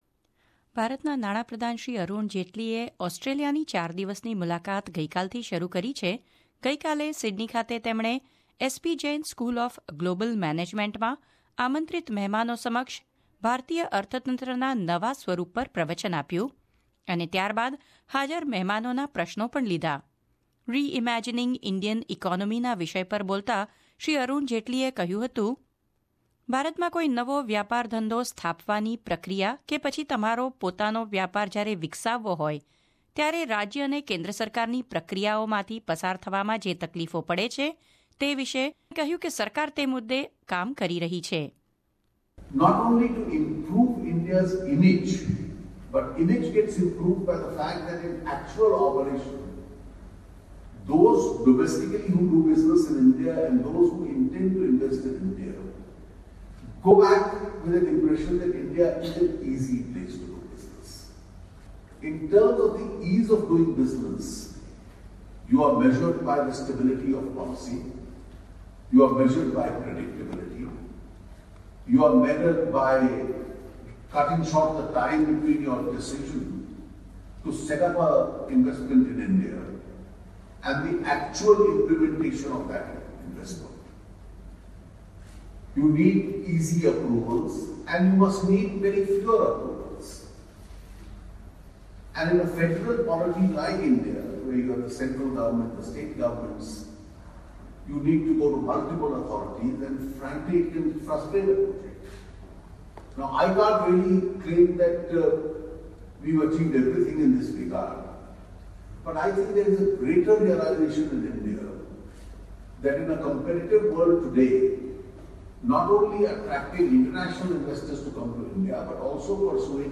It's the first visit of an Indian finance minister to Australia. Hon. Finance Minister of India Shri Arun Jaitley on the first day in Sydney addressed students at S P Jain college and invited guests on 'Reimagining Indian economy'.